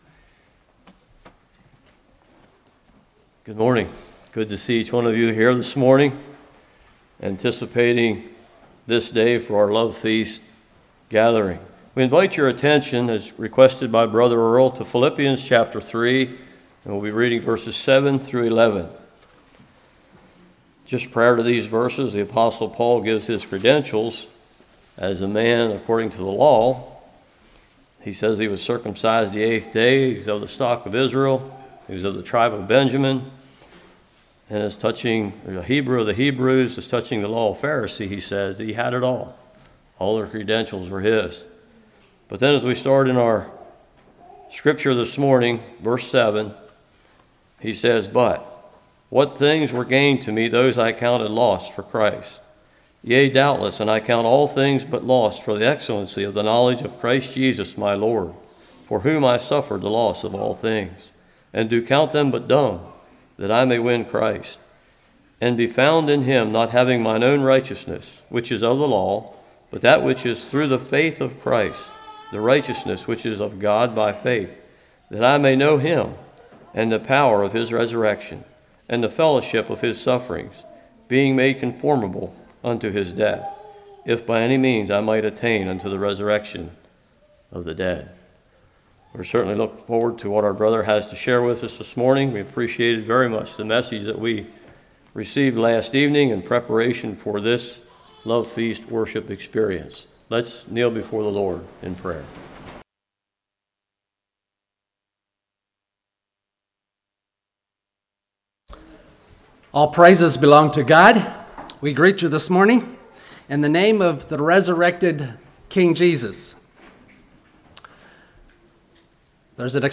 Philippians 3:7-11 Service Type: Morning Cherish And Prize Jesus Above All Else.